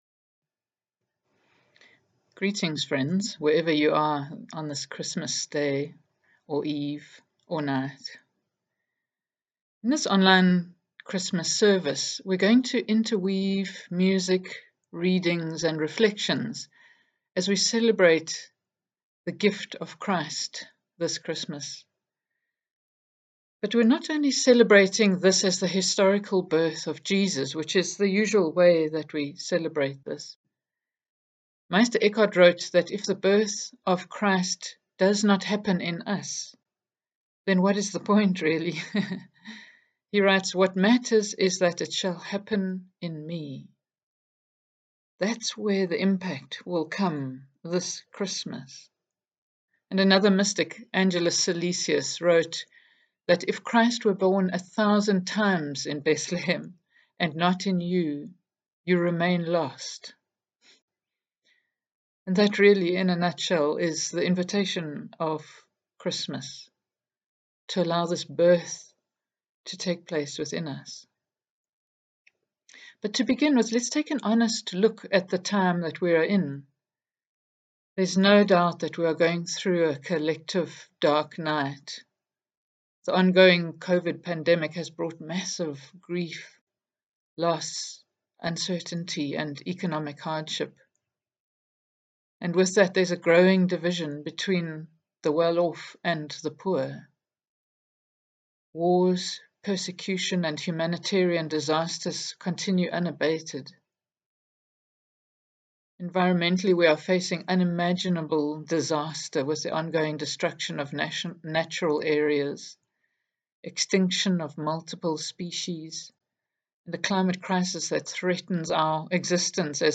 In this online Christmas reflection, we are going to interweave music, readings and reflections as we celebrate the coming of Christ this Christmas time.
Music: When a child is born (sung by Sinead O’Connor and Danny O’Reilly)